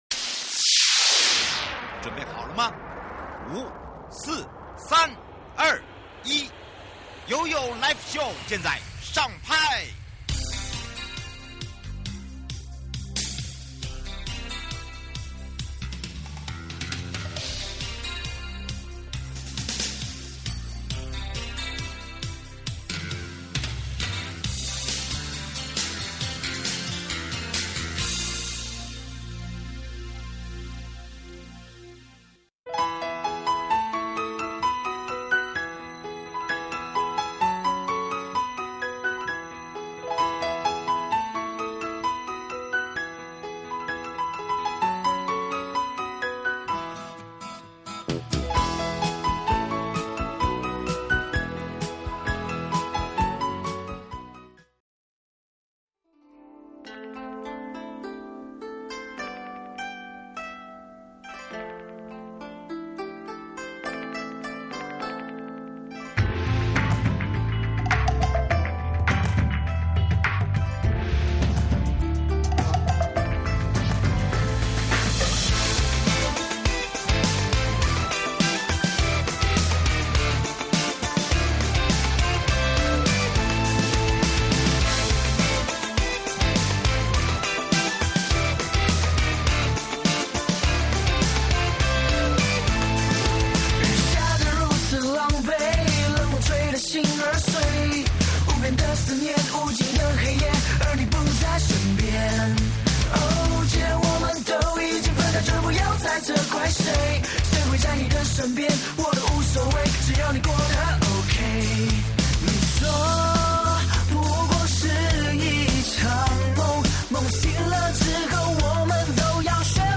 受訪者： 台中市觀光旅遊局 巫宗霖局長